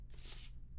纸张 " 用手抚摸对开的纸张
描述：单声道声音wav 24纸干。